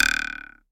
Звуки дротиков
Звук вонзания дротика в мишень